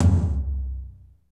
TOM F T L0UR.wav